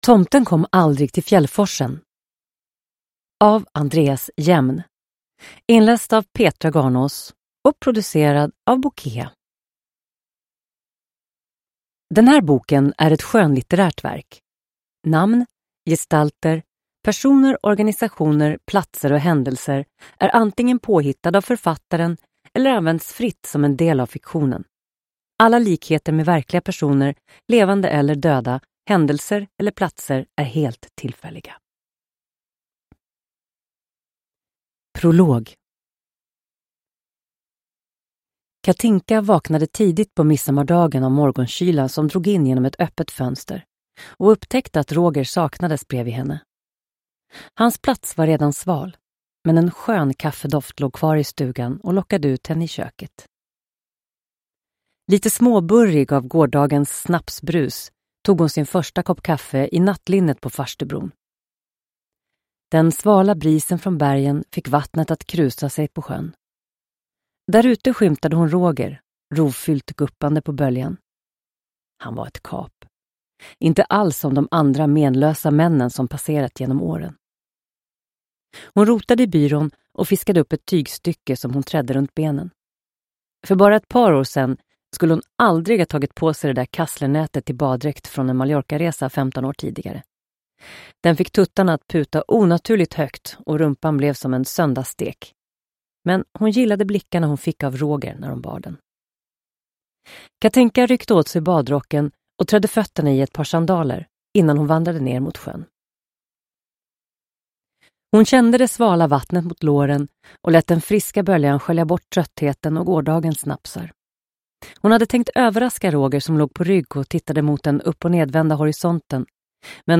(ljudbok)